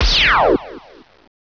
LASER01.WAV